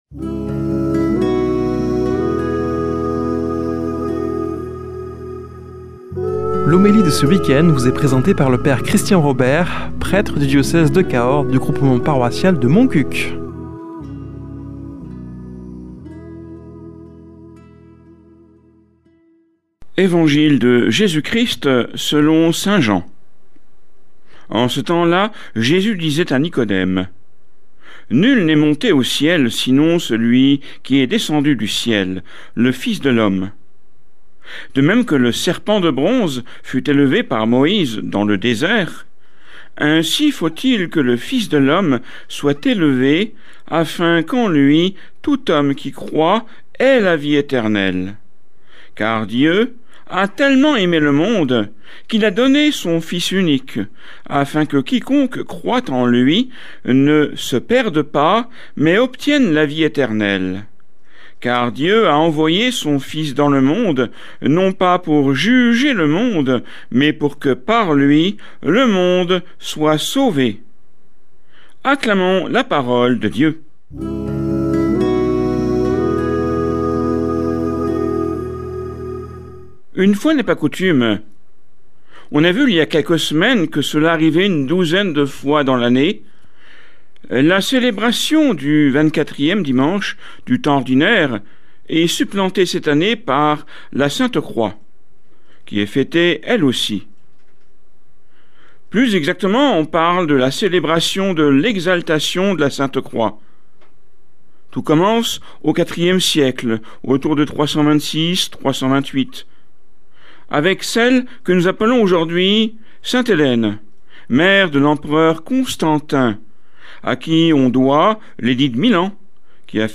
Homélie du 13 sept.